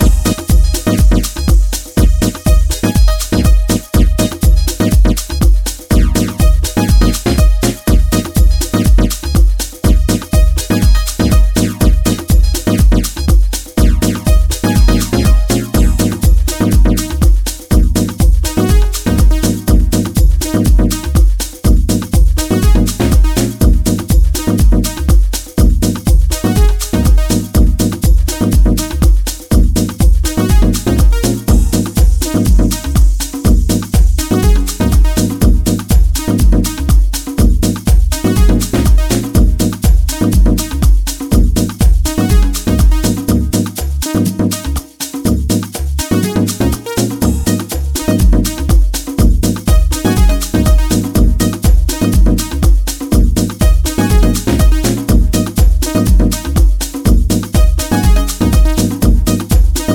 溌剌とコズミックなリードが冴えた
ハウスに流れるラテンの遺伝子をモダンなタッチで強調。